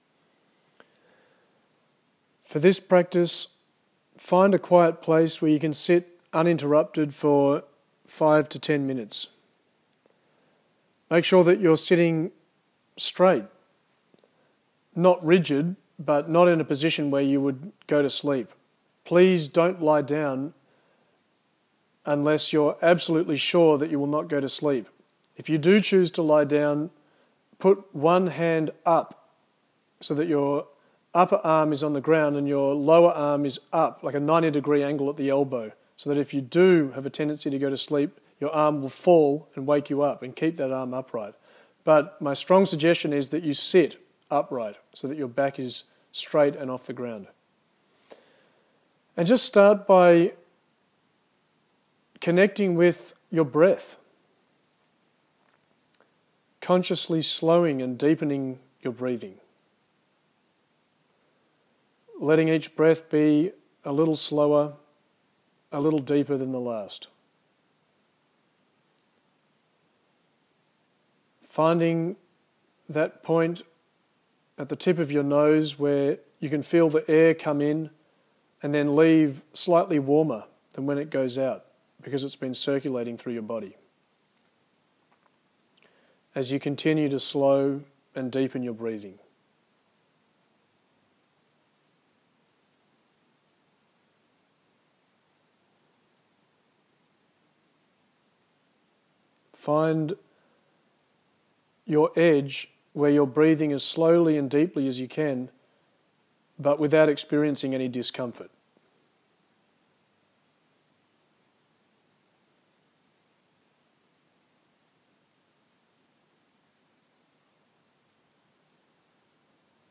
MEDITATIONS | Evolved Strategy